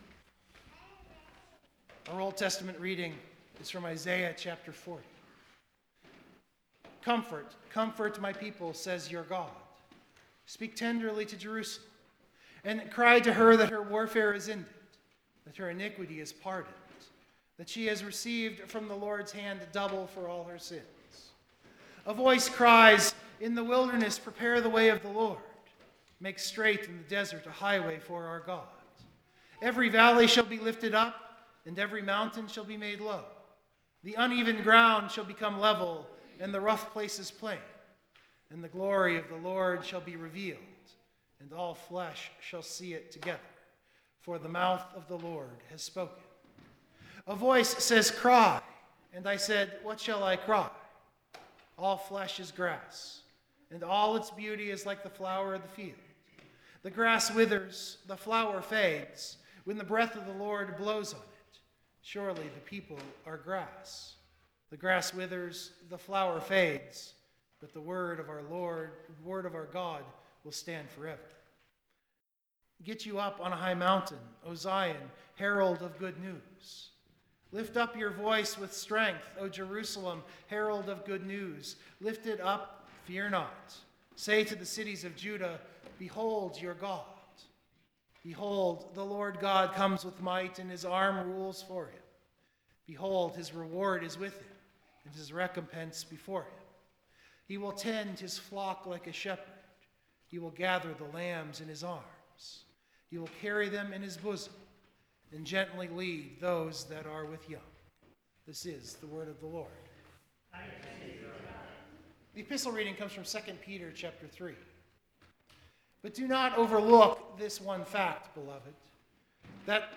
Worship note: I have left in two hymns. The one before the sermon and the one after.